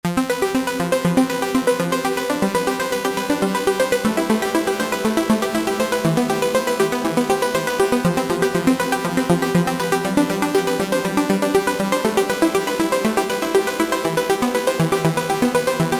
▶半音ごとにパターンを切り替えたアルペジオフレーズ
Serum2-Arp.mp3